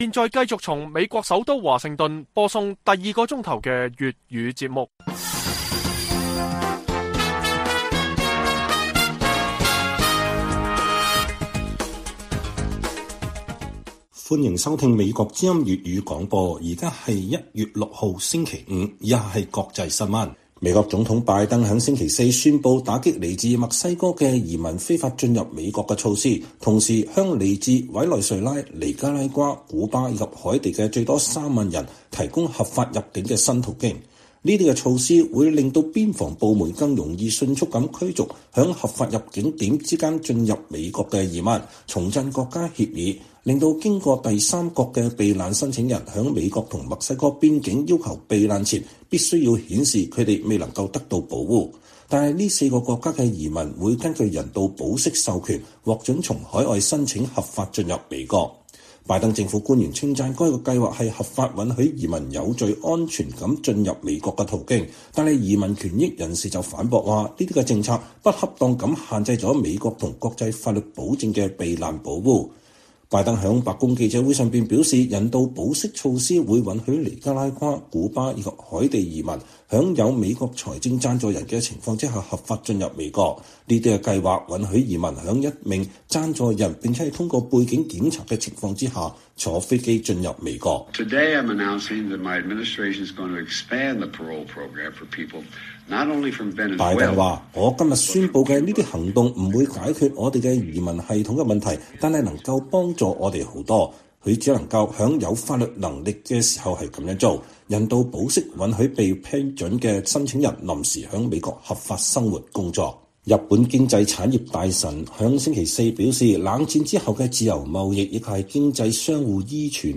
粵語新聞 晚上10-11點: 拜登宣布新的邊境措施